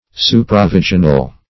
Search Result for " supravaginal" : The Collaborative International Dictionary of English v.0.48: Supravaginal \Su`pra*vag"i*nal\, a. (Anat.) Situated above or outside a sheath or vaginal membrane.